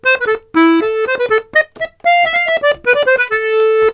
S120POLKA4-L.wav